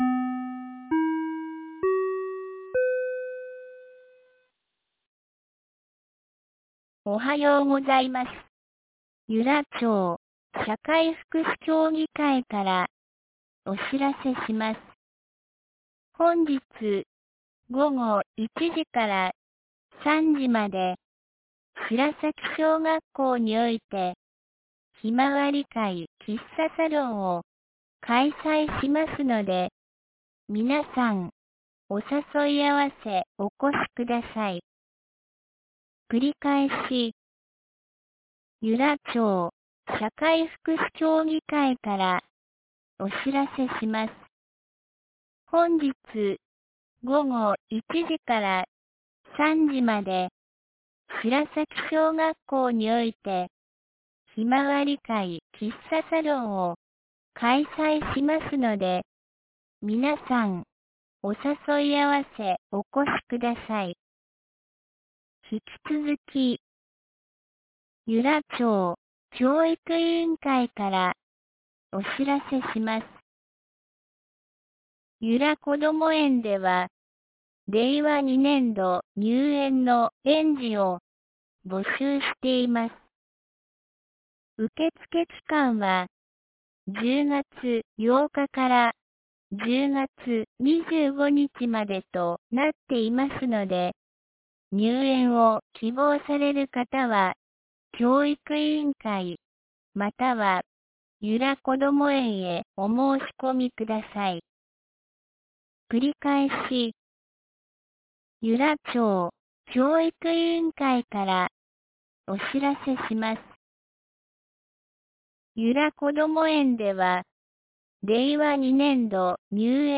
2019年10月08日 07時53分に、由良町から全地区へ放送がありました。
放送音声